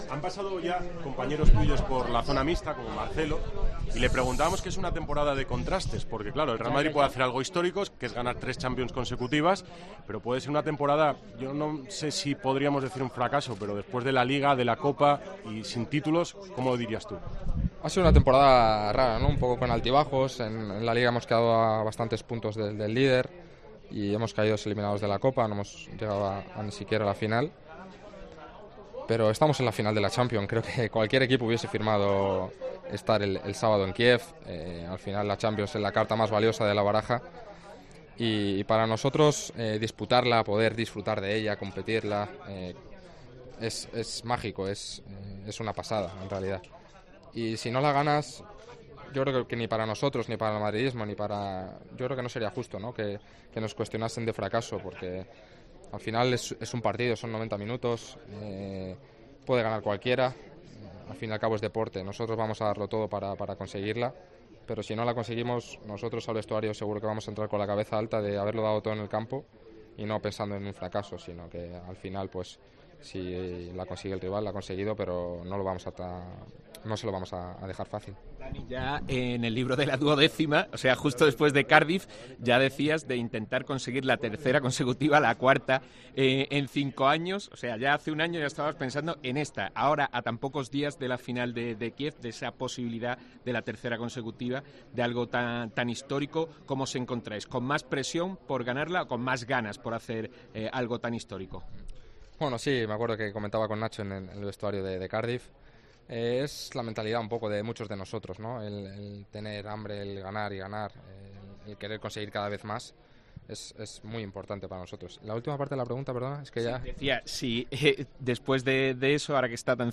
El jugador del Real Madrid atendió a los medios de comunicación en el Media Day del club: "Ha sido una temporada rara, con altibajos pero estamos en la final y cualquier equipo hubiese firmado estar el sábado en Kiev.